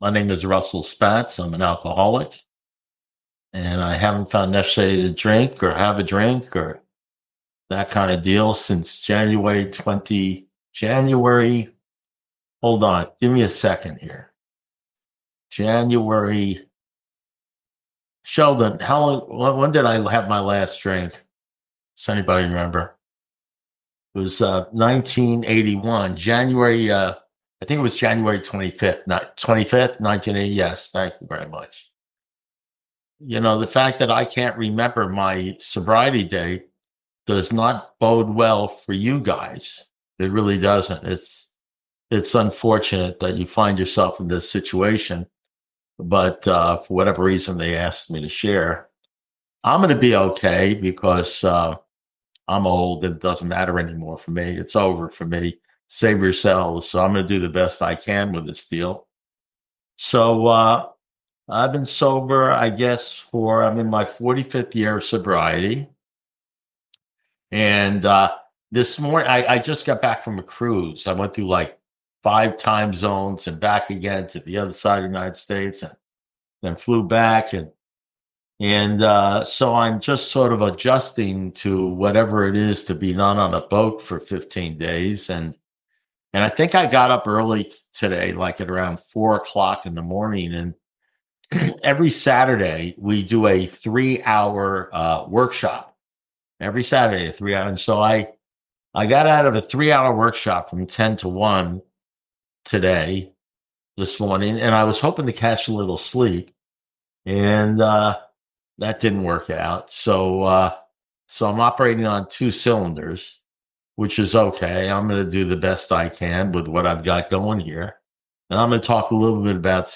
Alcoholics Anonymous Speaker Recordings
Outright Mental Defectives Group Zoom